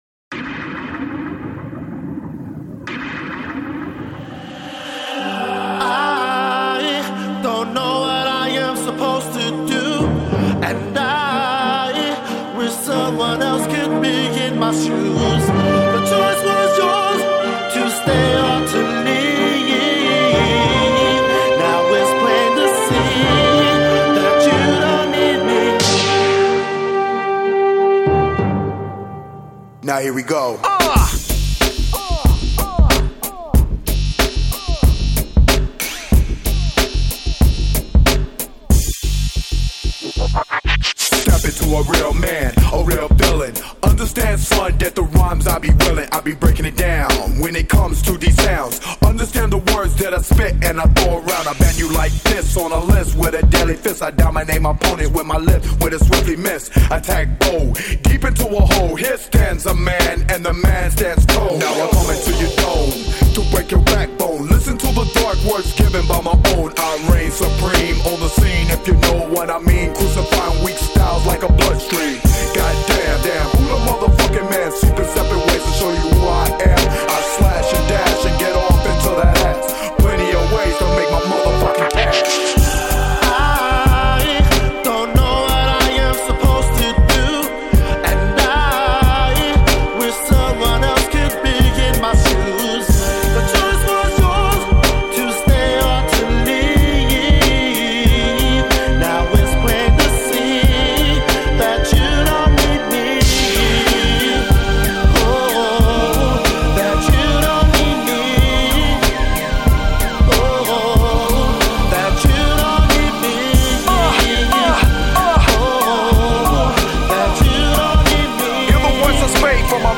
Жанр: rap
Рэп Хип-хоп.